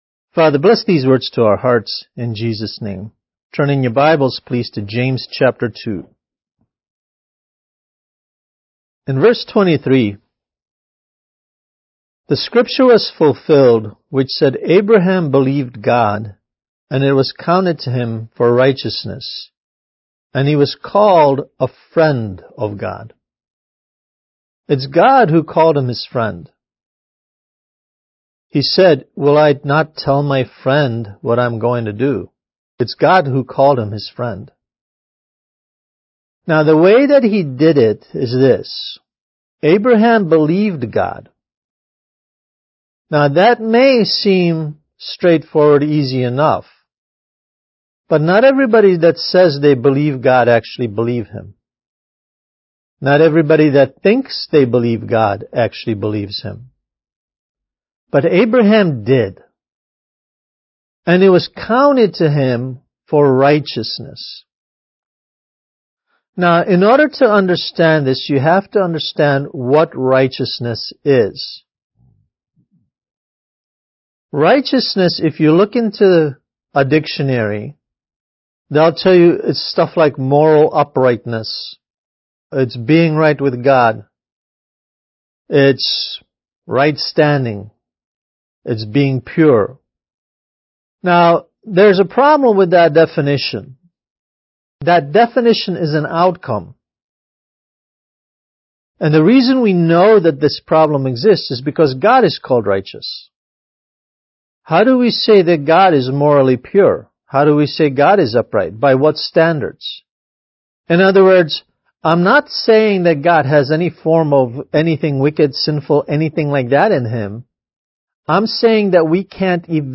Kids Message: How God Makes You Holy